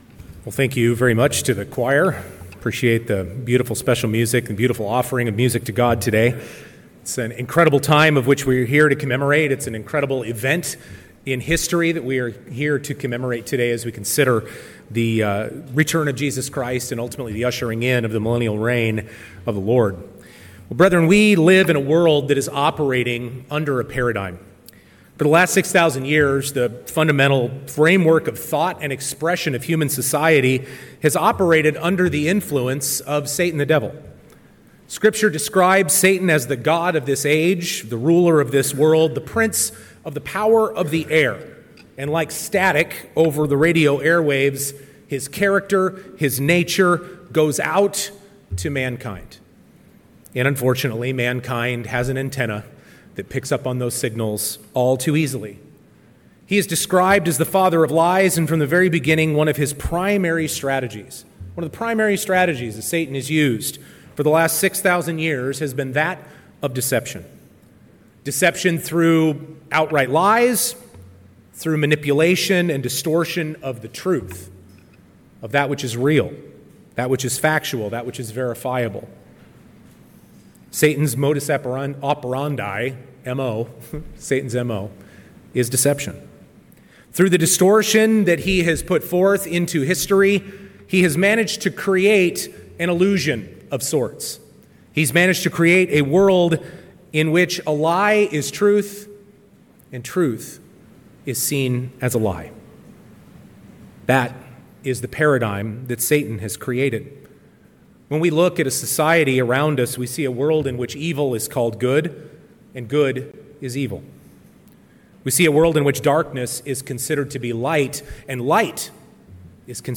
In this second part of this sermon series, we focus on this monumental tipping point, the sounding of the seventh Trumpet and the return of Jesus Christ, the moment when the illusion Satan has maintained for 6,000 years collapses under the weight of prophetic events, and reality, the truth of God is no longer able to be denied.